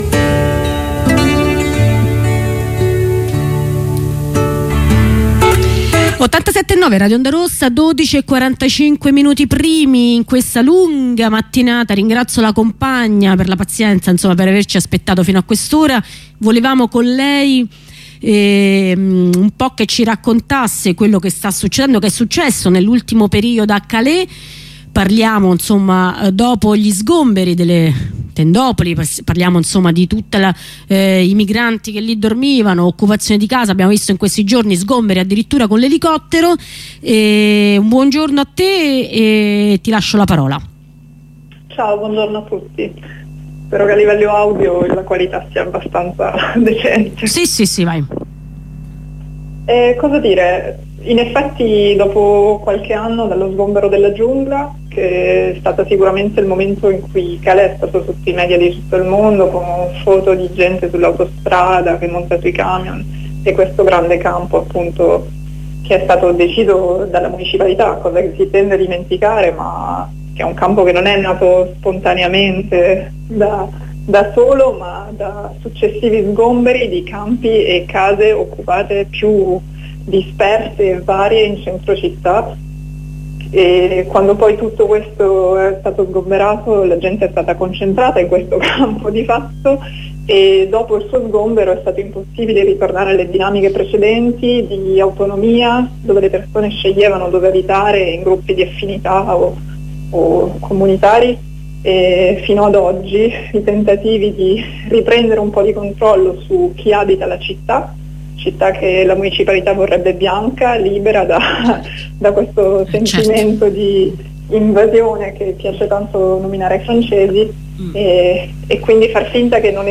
Corrispondenza da Calais